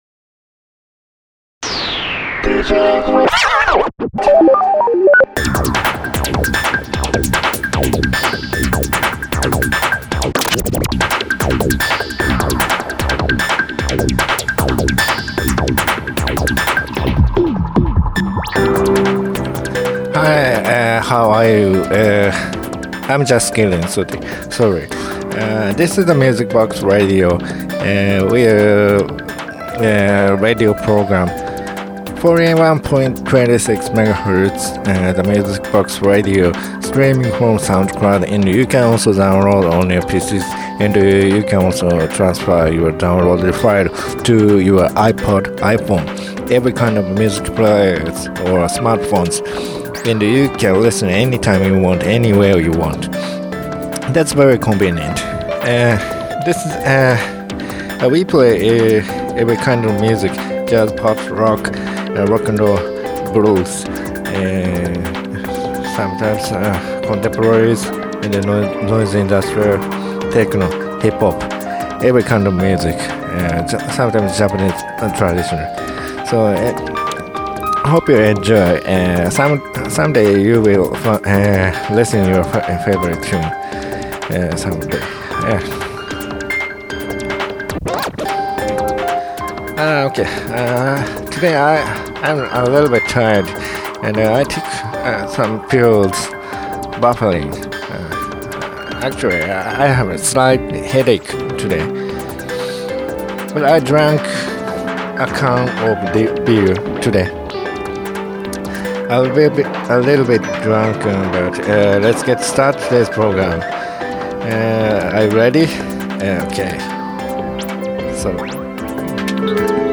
Detroit Electro
And J-Pops. 5.24upld